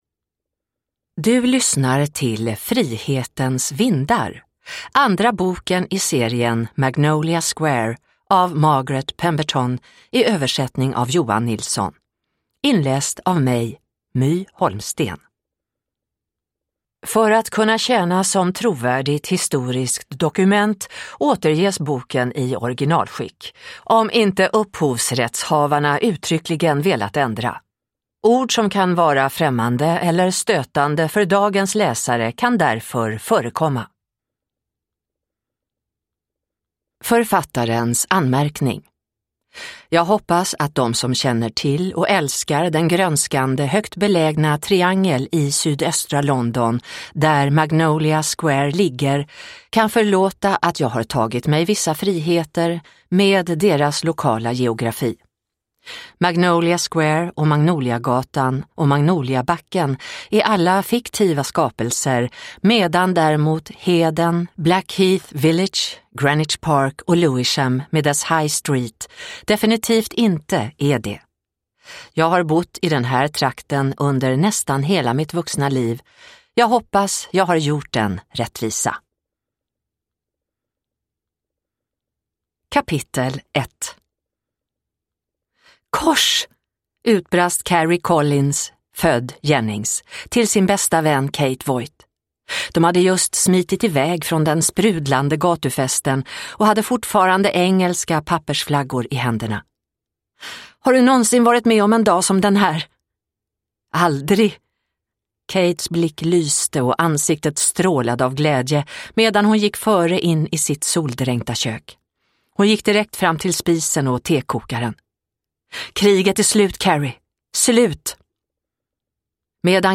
Frihetens vindar – Ljudbok – Laddas ner